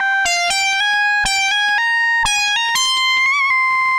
Index of /musicradar/80s-heat-samples/120bpm
AM_CopMono_120-C.wav